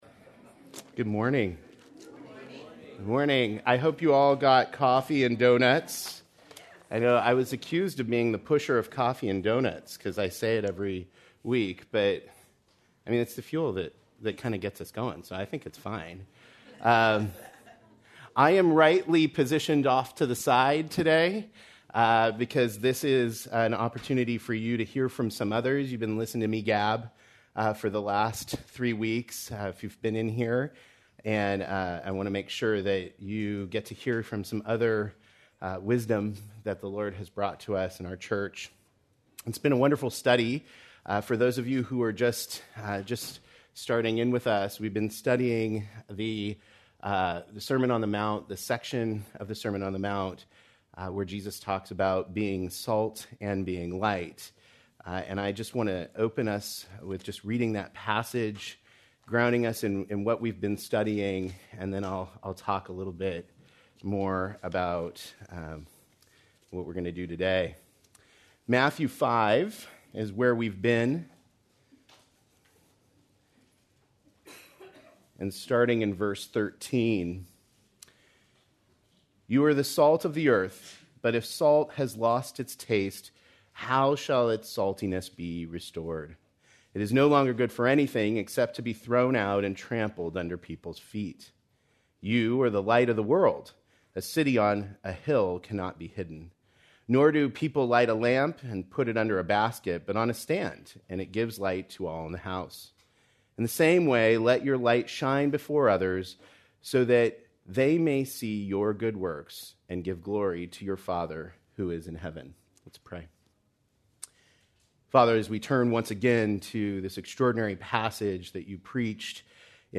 Salt & Light Panel Discussion: Living for Christ in Today’s Culture